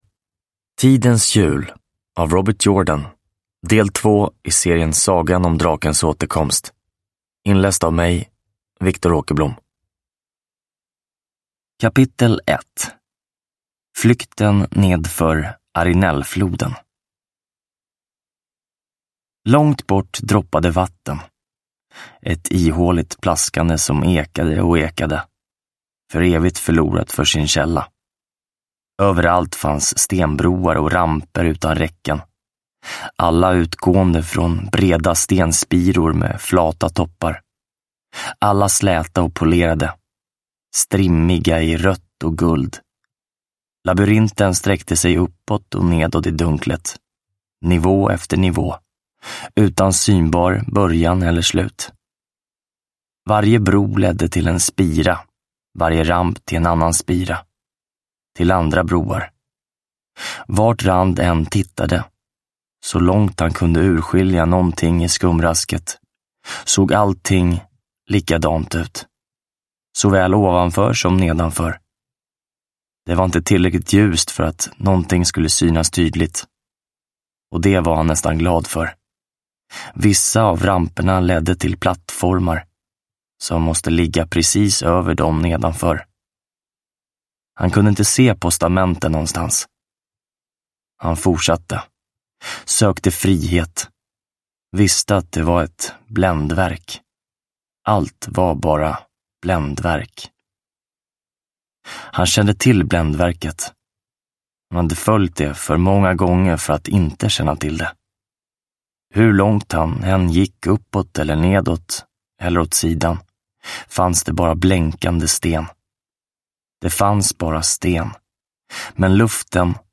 Tidens hjul – Ljudbok – Laddas ner